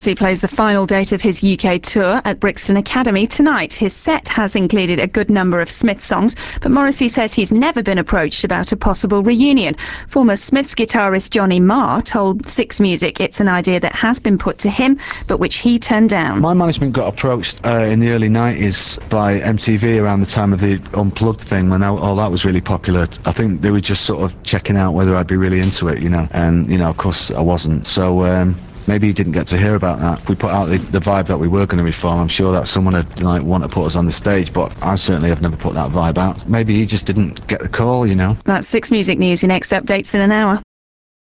Today's (Tuesday) BBC 6 Music news featured some comments from Johnny Marr regarding Morrissey's claim that no-one had ever tried to get The Smiths back together. Interestingly, Marr claims MTV approached his management in the early nineties about reforming for an MTV Unplugged performance.
marr_reunion_comments.rm